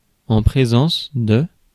Ääntäminen
US : IPA : [ˈpɹɛz.əns]